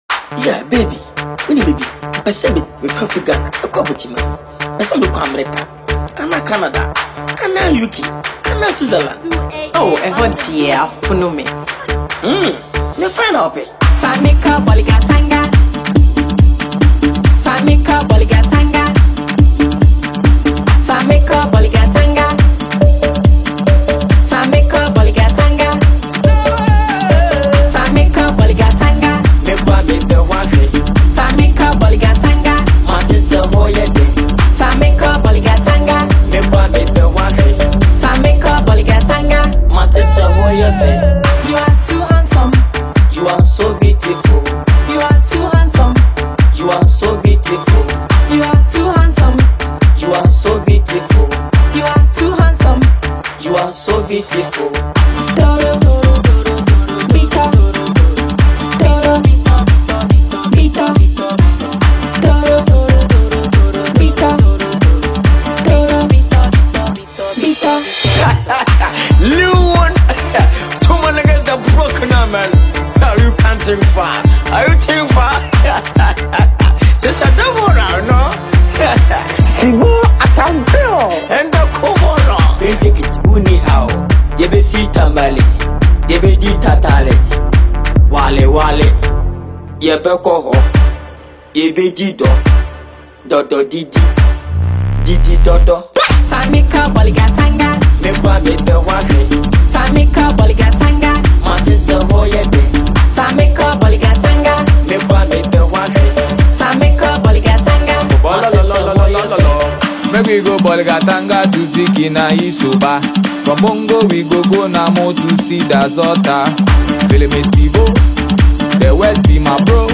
signature sweet, deadpan vocals
a burst of high-energy Twi humor
Alternative Highlife